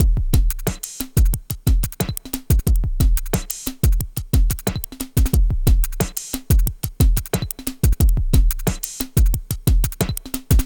Downtempo 19.wav